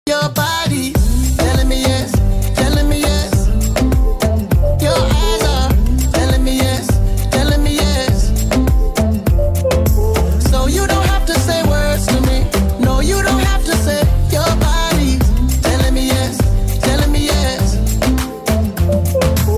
which was very beautifully play from the instrumental
English Ringtones